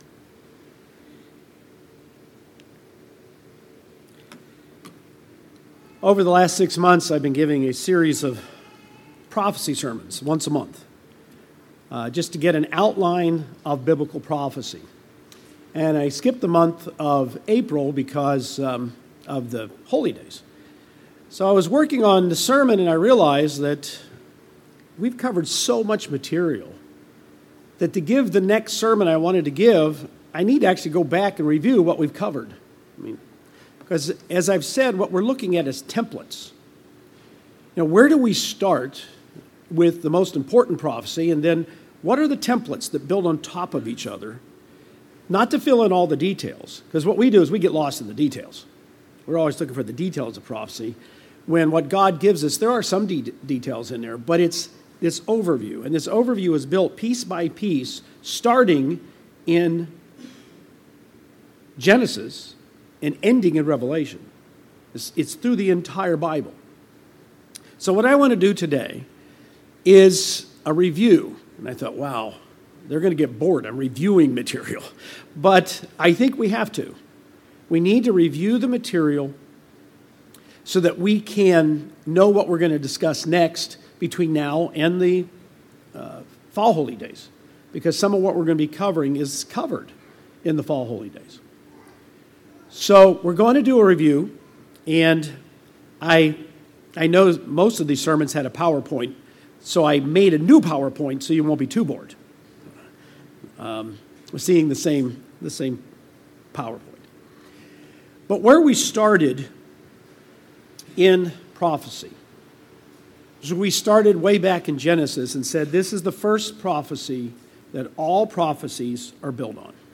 This a review of the prophecy sermons given in 2023 and 2024.